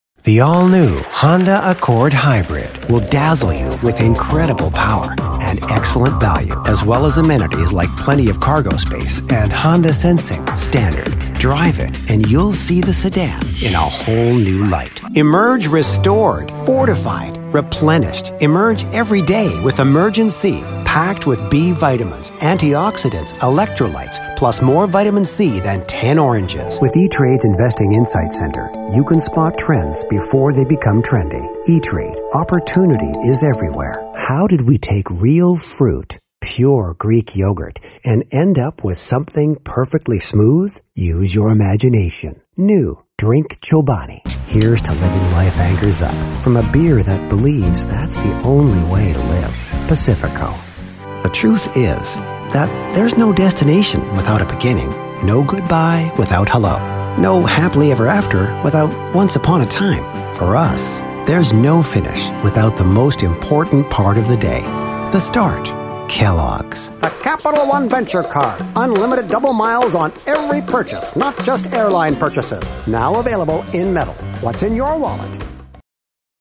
Commercial